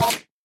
mob / endermen / hit3.ogg
should be correct audio levels.
hit3.ogg